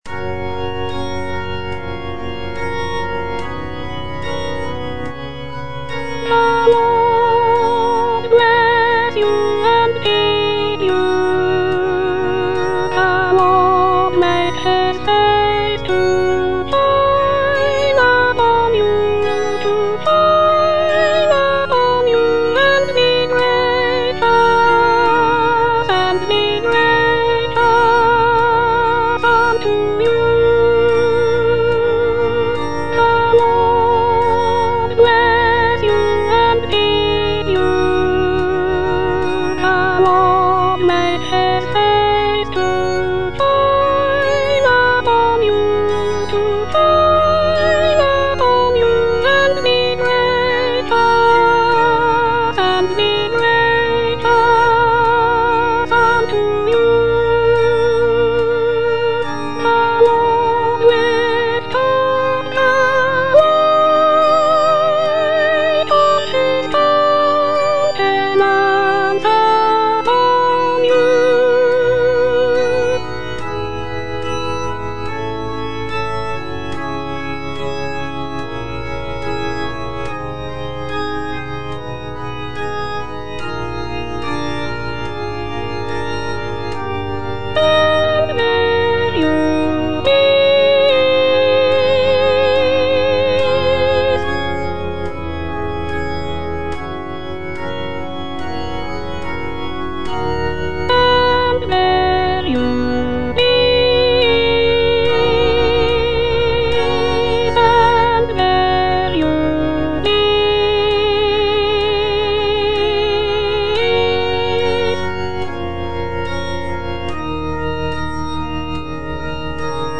Soprano (Voice with metronome)
is a choral benediction